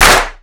Clap20.wav